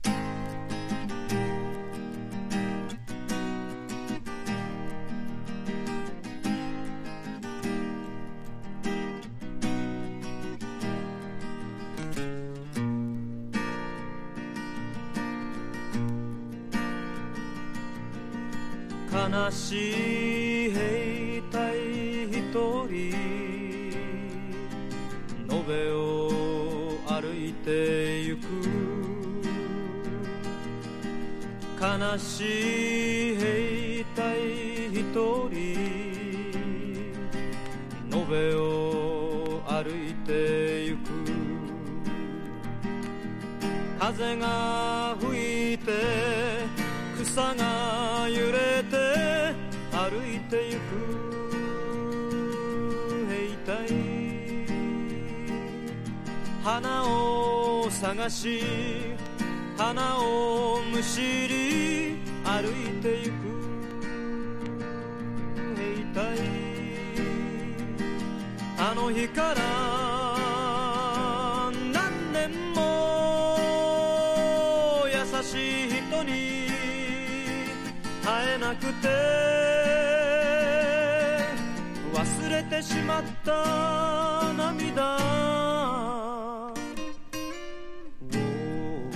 SSW / FOLK# 和モノ / ポピュラー